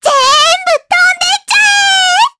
Luna-Vox_Halloween_Skill3_jp.wav